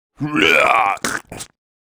owl-puke.wav